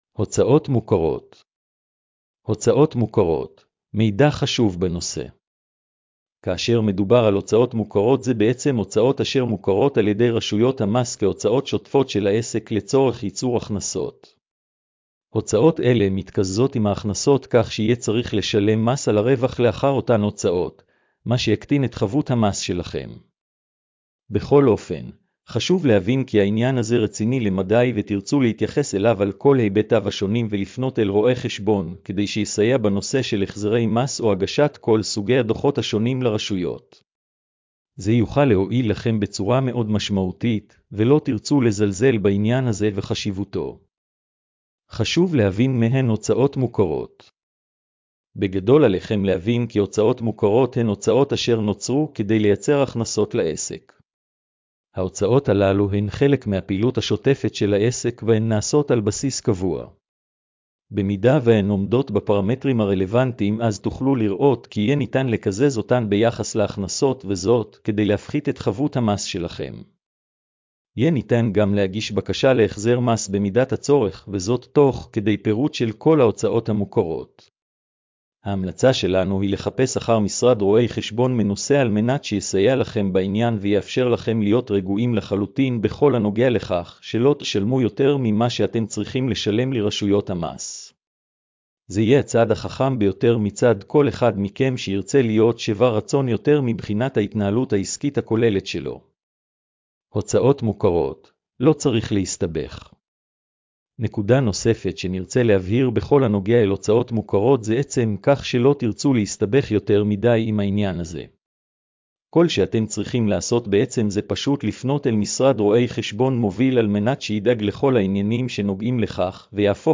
הקראת המאמר לבעלי מוגבלויות: